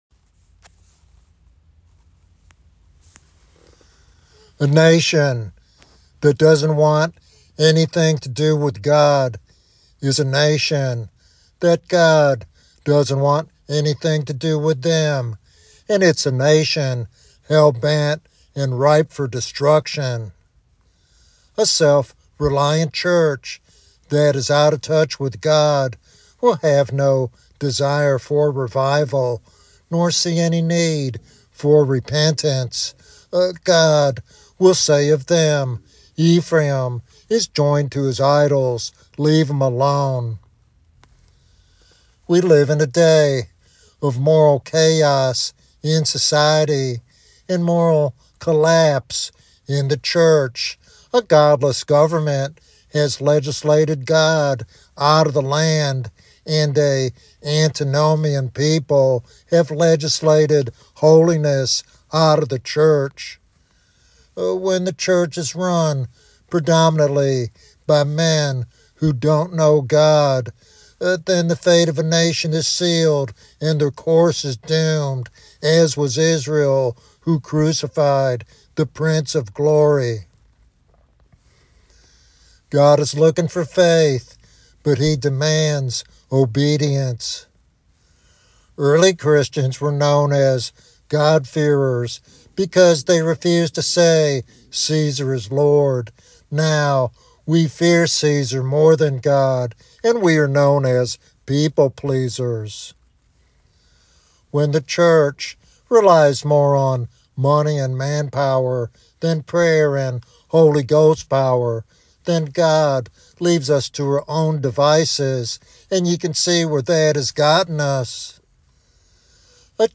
Why is revival important according to the sermon?